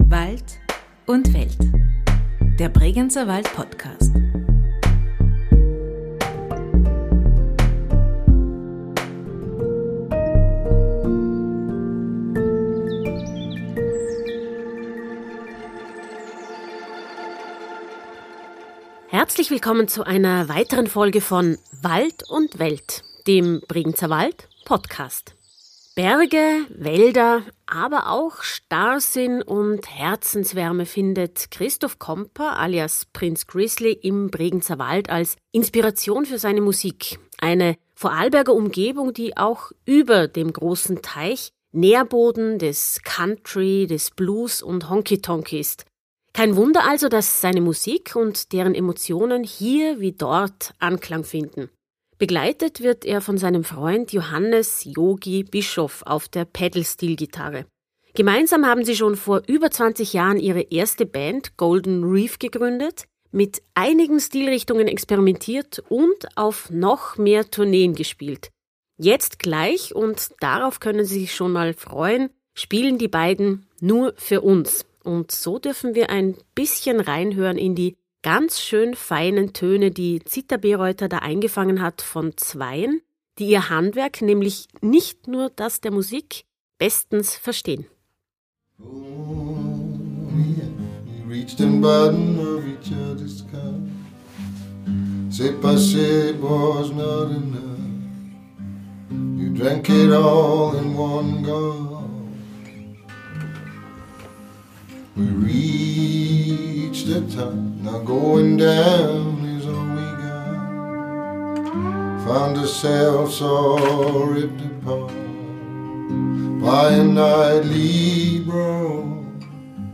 in einem Musikstudio in Egg im Bregenzerwald eingefangen